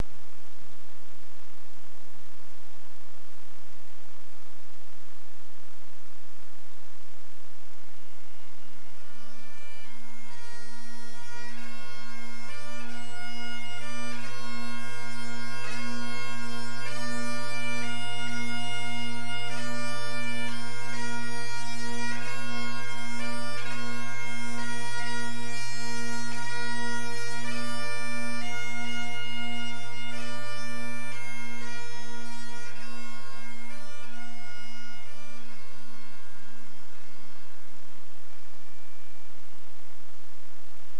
§Pipes
The mournful pipes honor the Fallen Firefighters of 9/11.